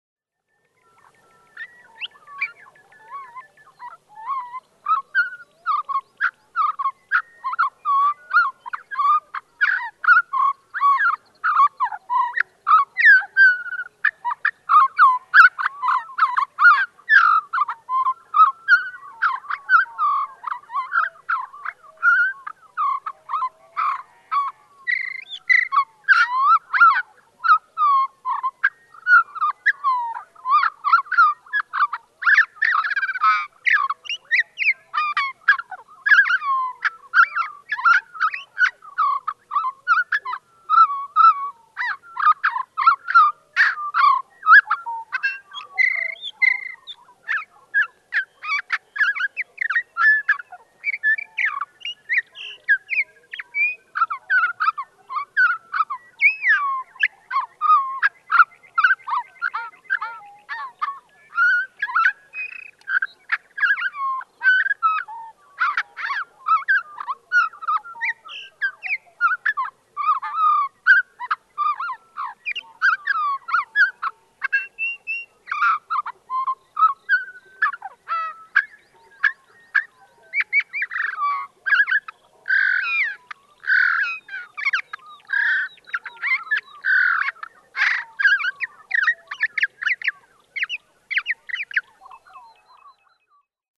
Sage thrasher
Song, a torrent of half-second sound bites.
Arapaho National Wildlife Refuge, Walden, Colorado.
♫378 One song from above at half speed. (1:33)
378_Sage_Thrasher.mp3